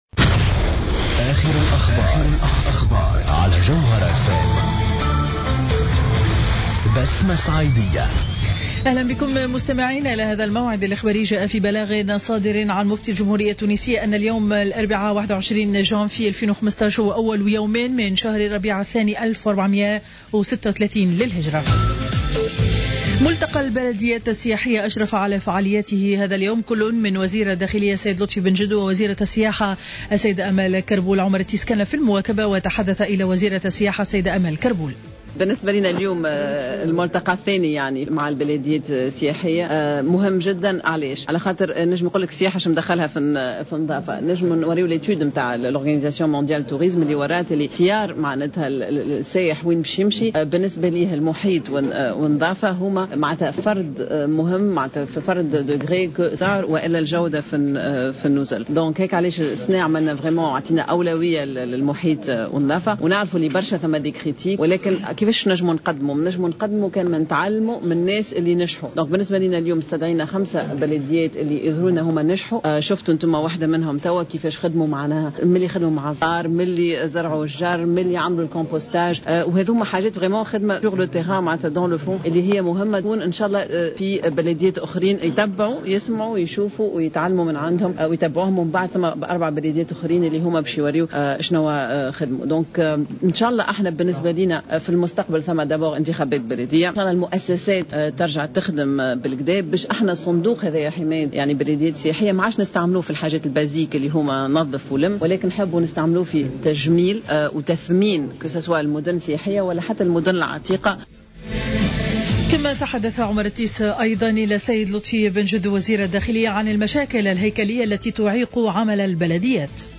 نشرة أخبار منتصف النهار ليوم الاربعاء 21-01-15